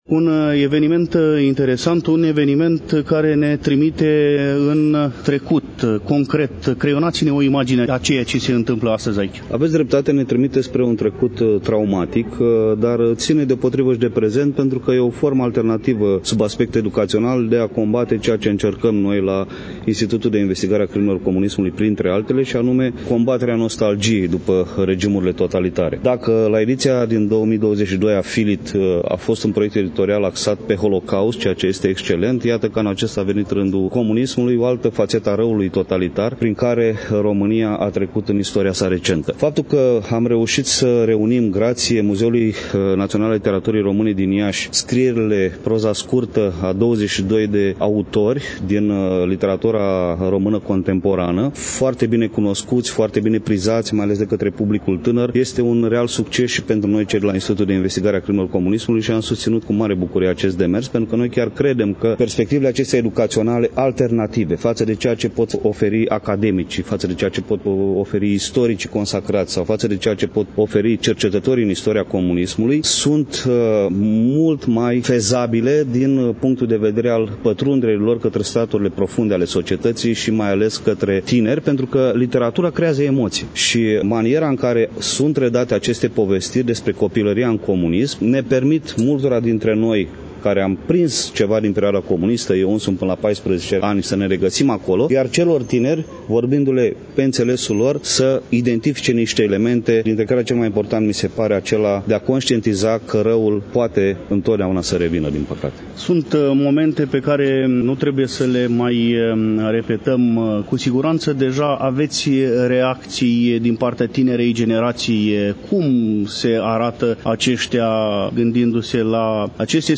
Câteva amintiri din copilăria trăită în comunism, constituie un alt subiect pe care l-am abordat în interviul cu profesor Daniel Șandru, președinte executiv al Institutului de Investigare a Crimelor Comunismului și Memoria Exilului Românesc.